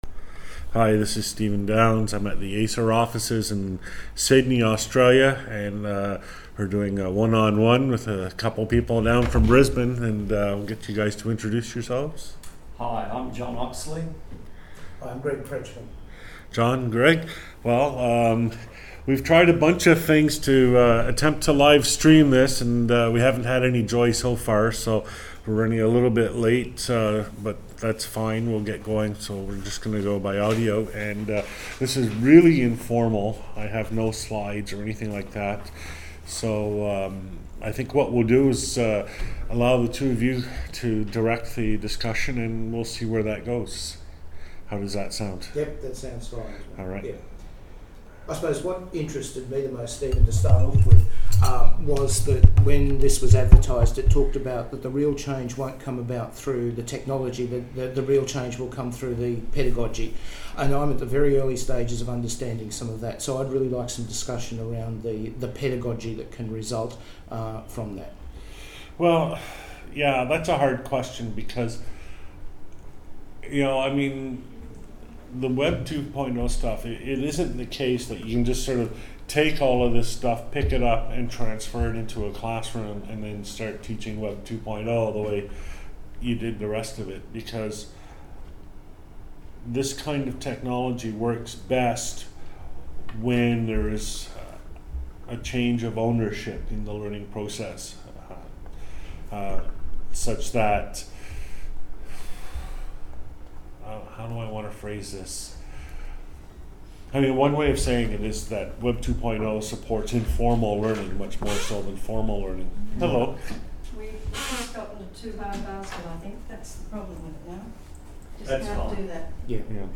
Recorded at the ACER offices, Sydney, Australia, March 31, 2009.
Interview